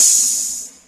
Closed Hats
HiHat (28).wav